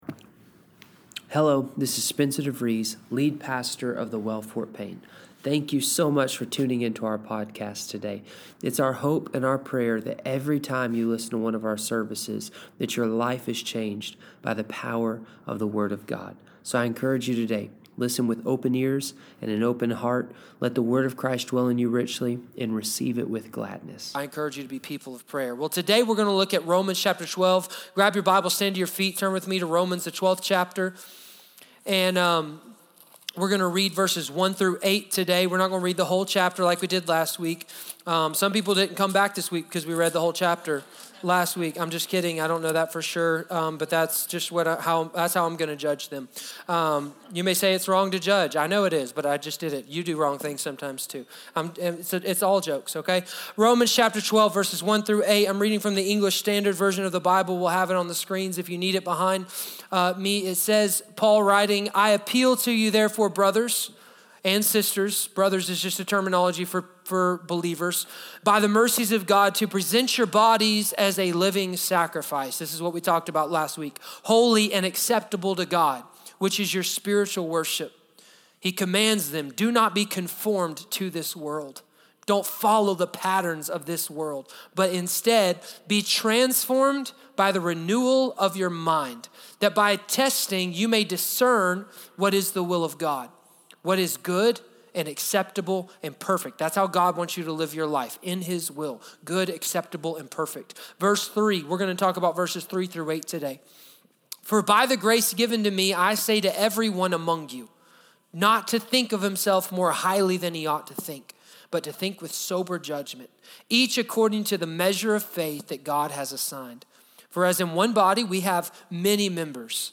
Sermons | The Well Fort Payne